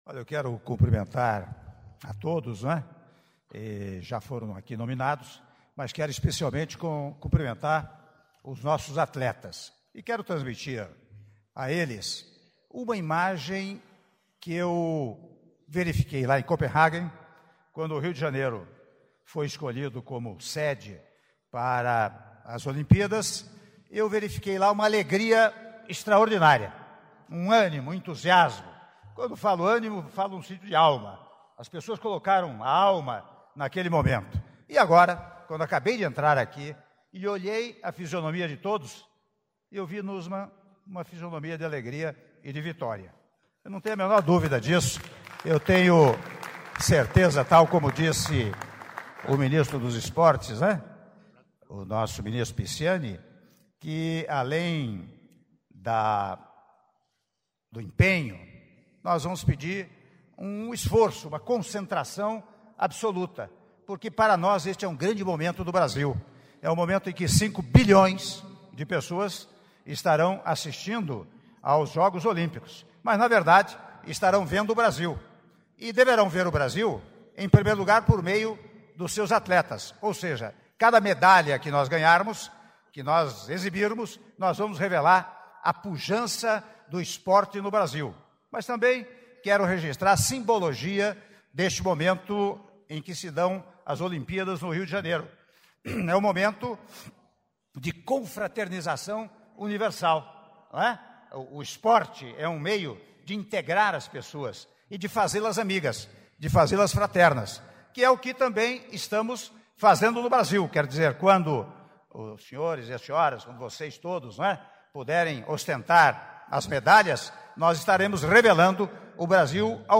Áudio do discurso do presidente da República em exercício, Michel Temer, durante Cerimônia de apresentação da Delegação de Atletas Olímpicos (03min19s) - Brasília/DF